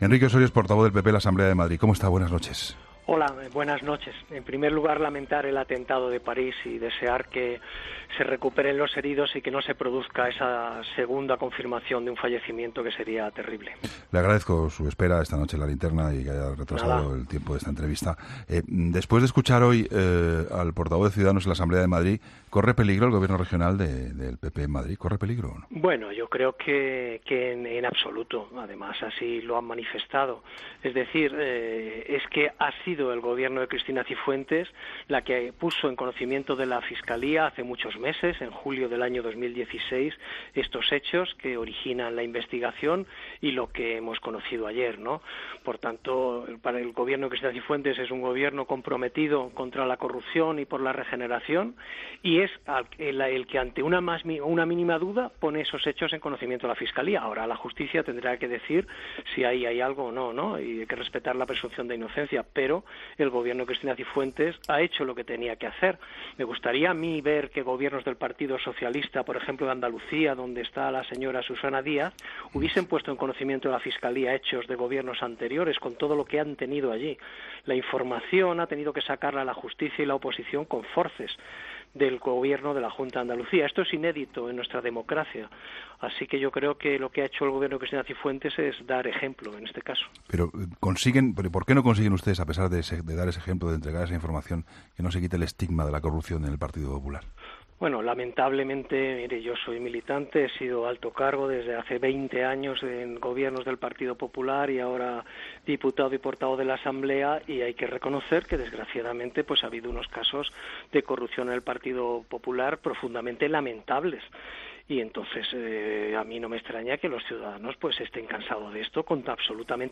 Entrevista a Enrique Ossorio